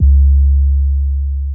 BWB UPGRADE3 COMPRESSED 808 (4).wav